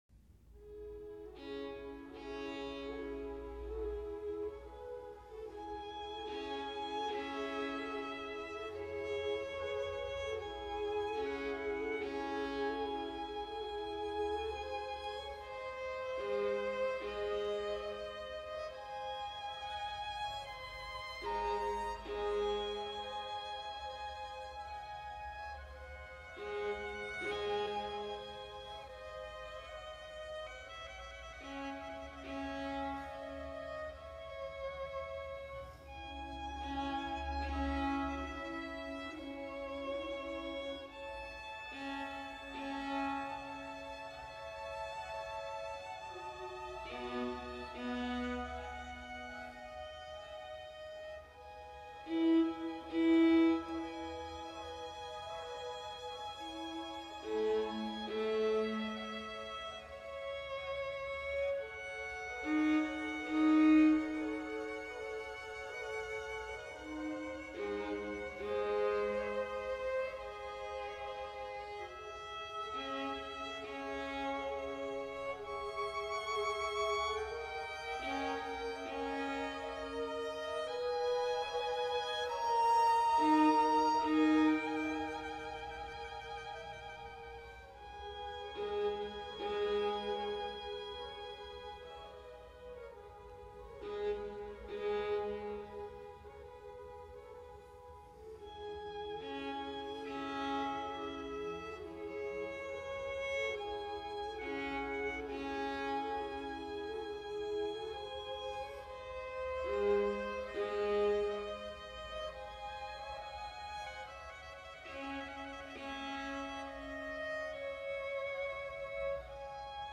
E大调，作品第8号，第一首
II.Largo e pianissi mo sempre
第二乐章：牧羊人，最缓板
慢乐章于字母F处开始，乐队描写牧羊人小睡(独奏小提琴的徐缓而流畅的旋律)外加一个显然不能纳入十四行诗的描绘性片段。